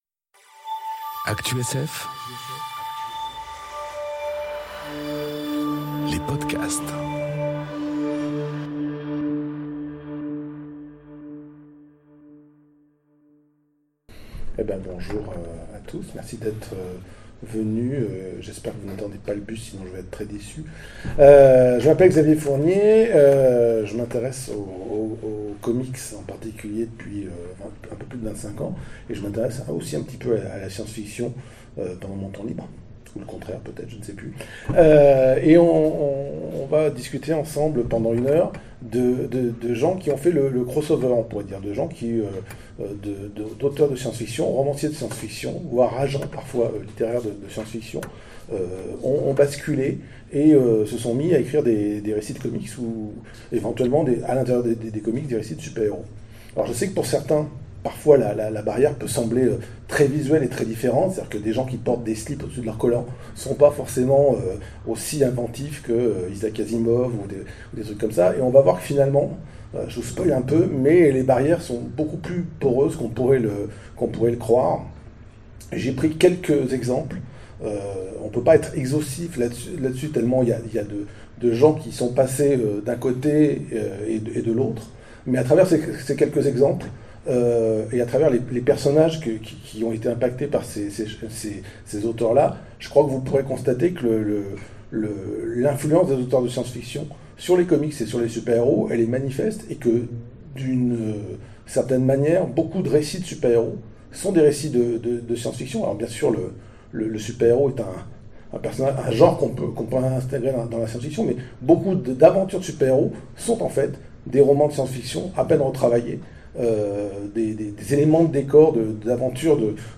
Captation de la conférence Les écrivains de Science-Fiction, scénaristes de comics